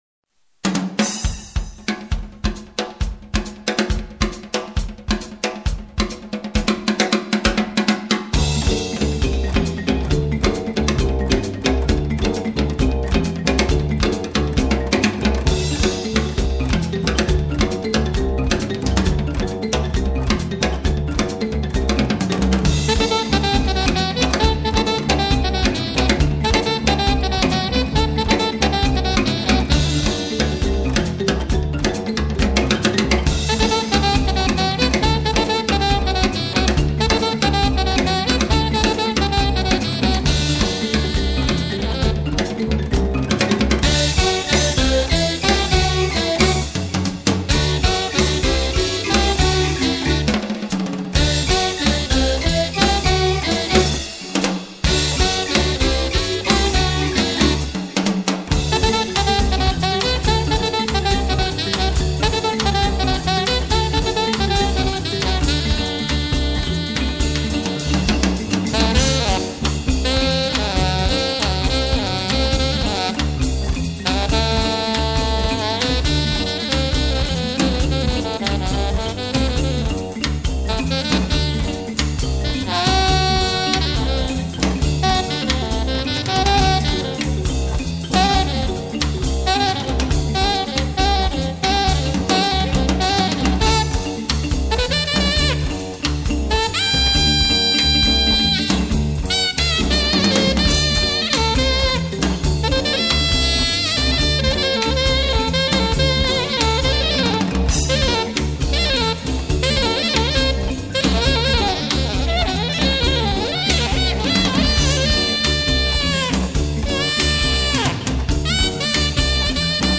Jazz graduate piano teacher